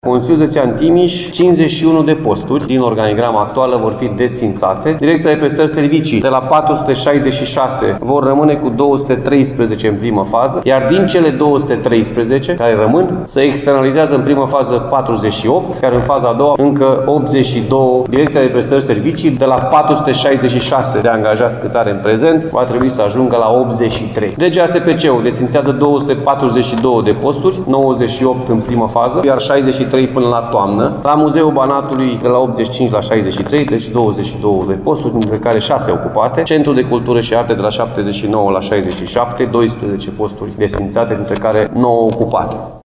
01-Alfred-Simonis.mp3